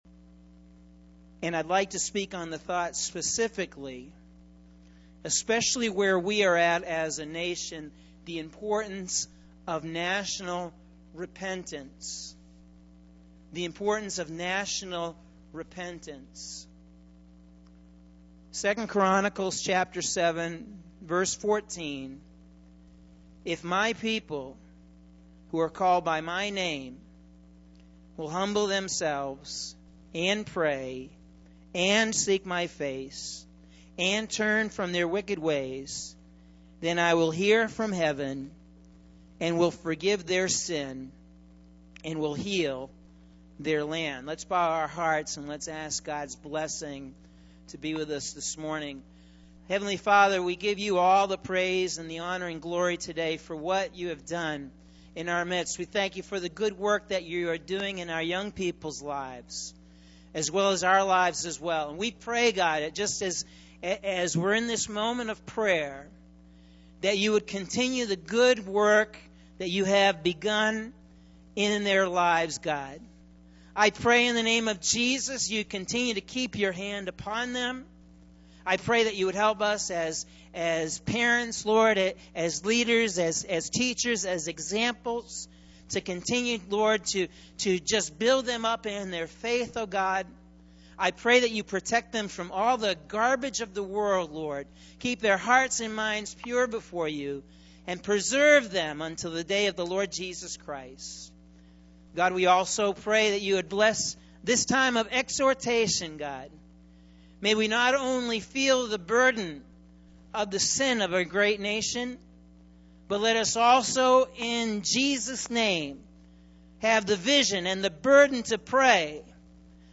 Sunday October 31st – AM Sermon – Norwich Assembly of God